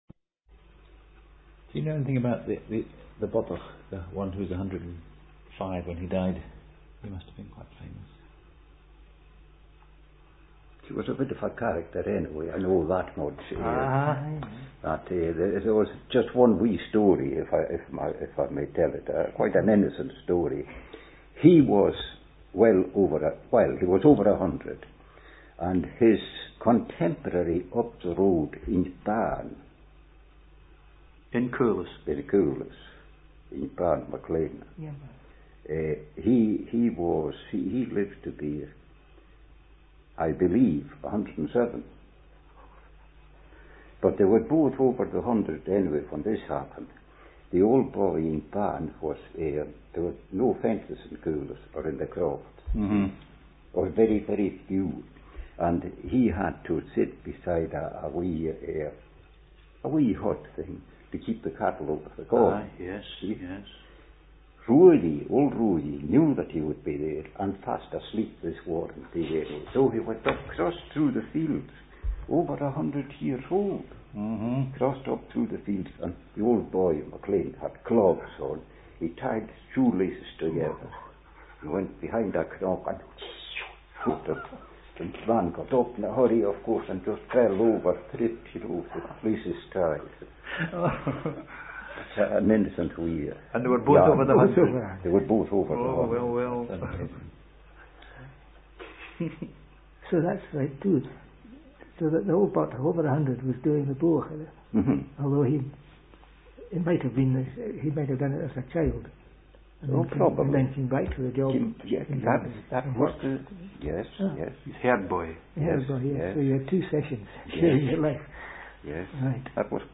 Sound clip in English
In a discussion about families living in Caoles in 1881